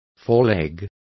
Complete with pronunciation of the translation of foreleg.